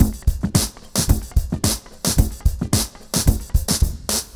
RemixedDrums_110BPM_22.wav